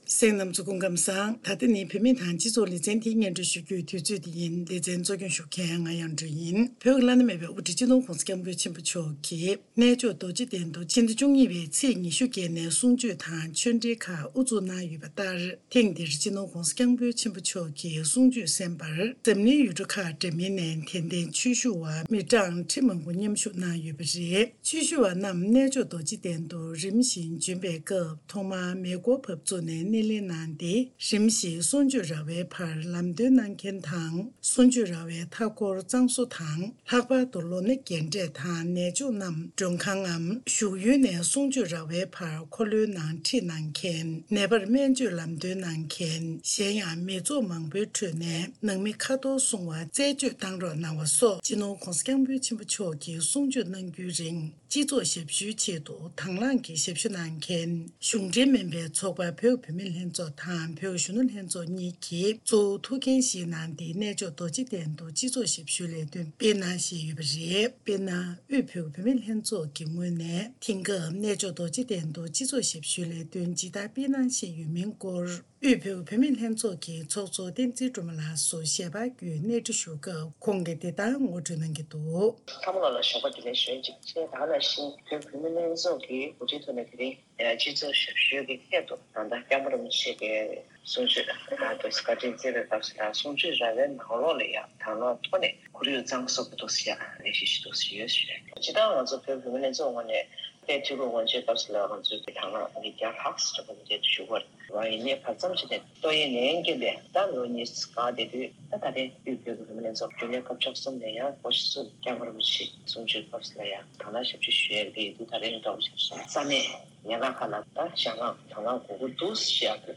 འབྲེལ་ཡོད་མི་སྣར་གནས་འདྲི་ཞུས་ཏེ་ཕྱོགས་སྒྲིགས་དང་སྙན་སྒྲོན་ཞུས་པར་གསན་རོགས་ཞུ།